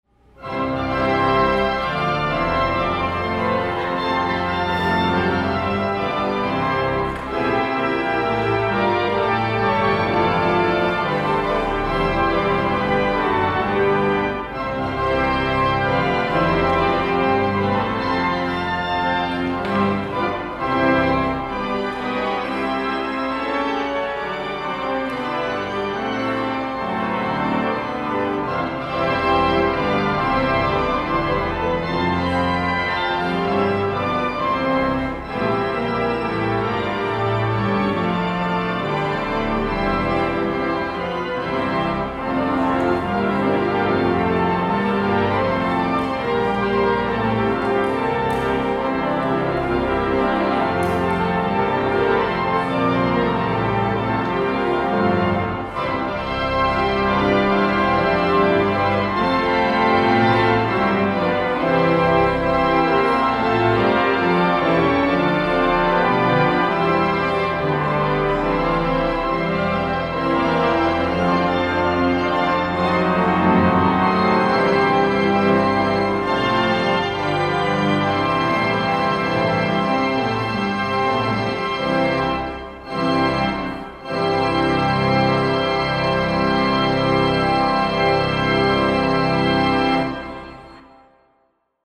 Organ
Built during the first half of 1929, Opus 805 of the Skinner Organ Company is an extremely important instrument in the city of Lancaster.
Otterbein Organ
closing-song-otterbein-organ.mp3